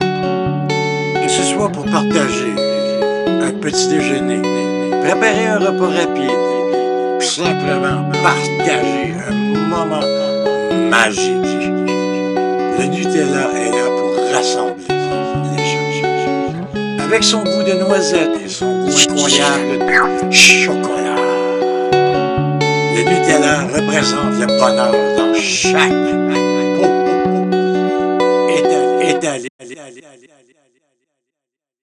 Commercial Mutella 2 Wav